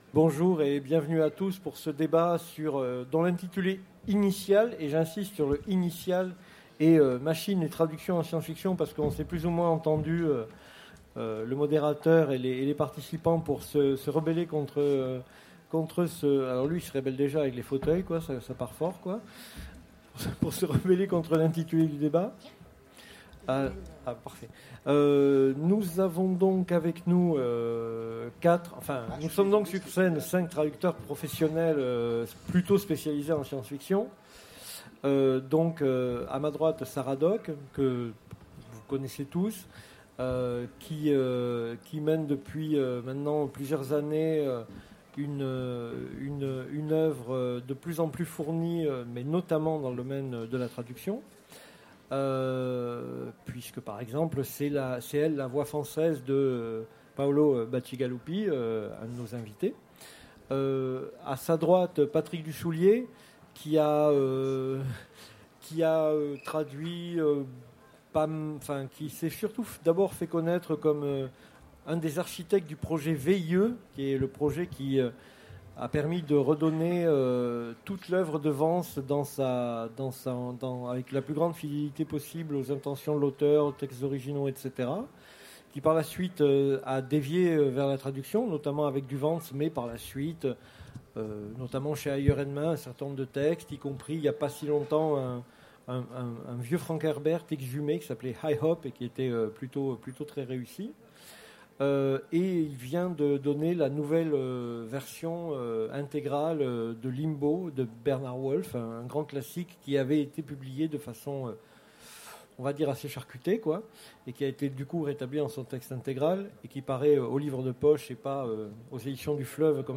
Utopiales 2016 : Conférence Machines et traductions en science-fiction